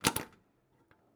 Sci-Fi Sounds / Mechanical / Device Toggle 12.wav
Device Toggle 12.wav